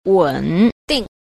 5. 穩定 – wěndìng – ổn định